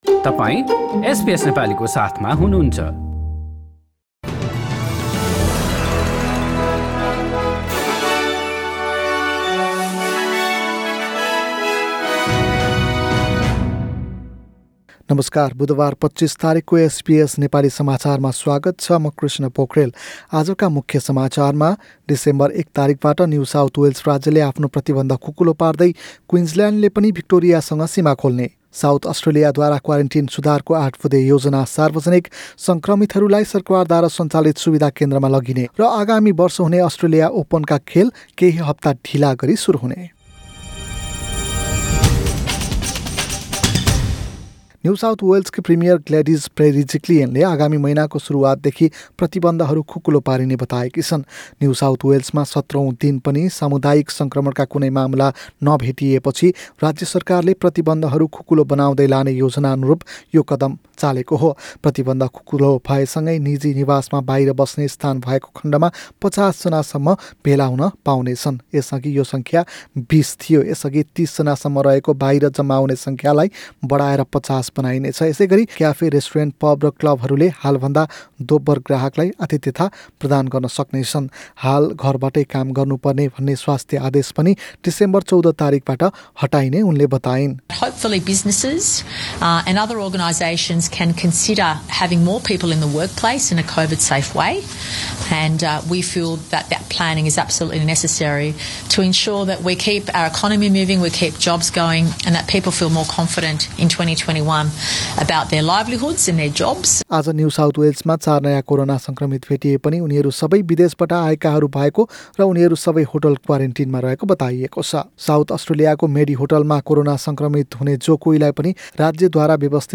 एसबीएस नेपाली अस्ट्रेलिया समाचार: बुधबार २५ नोभेम्बर २०२०